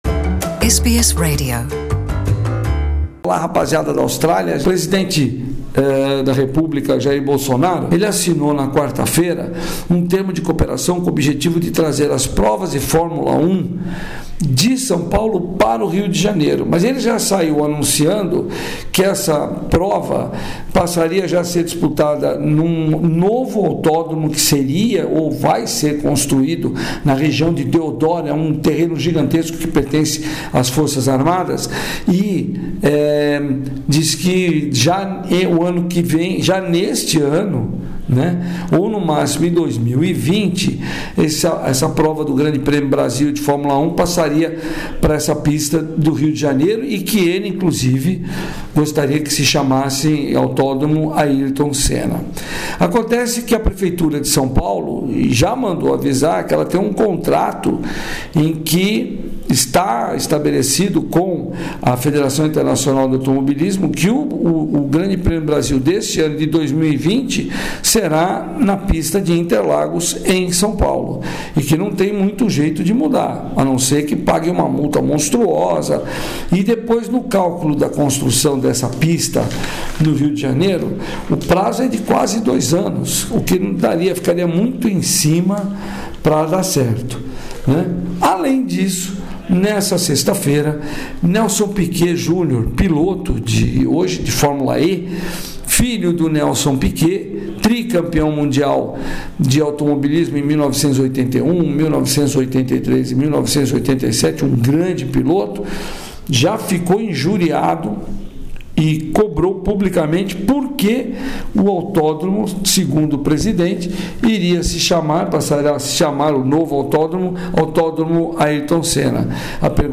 Ouça boletim esportivo do correspodente da SBS